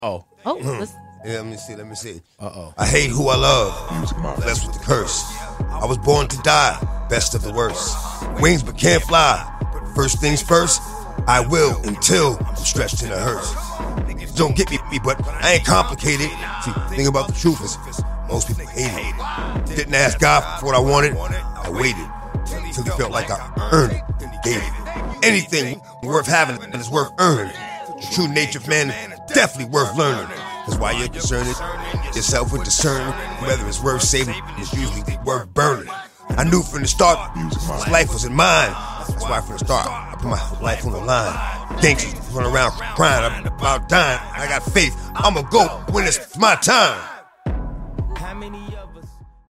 DMX spits verse on the radio for Big Boy Neighborhood